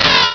Cri de Munja dans Pokémon Rubis et Saphir.